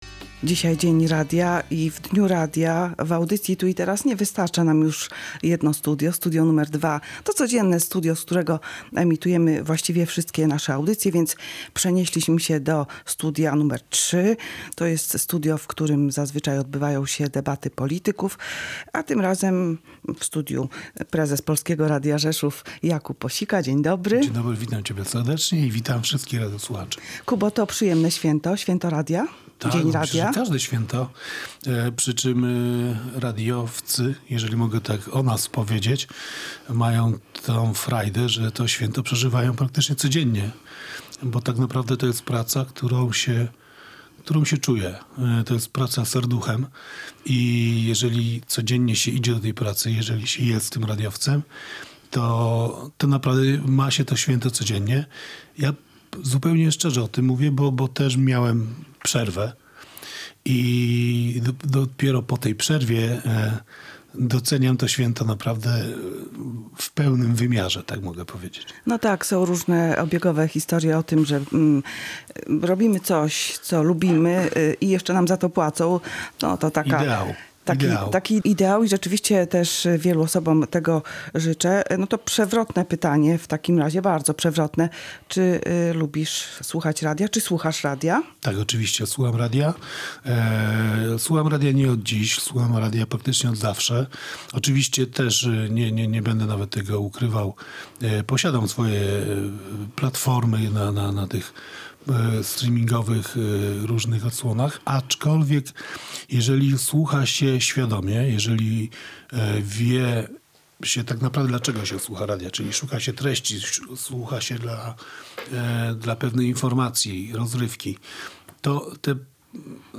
Rozmowa w Dniu Radia w Polsce (11 kwietnia) była też okazją do stwierdzenia, że dla większości pracowników radia jest ono nie tylko miejscem pracy, ale przede wszystkim pasją.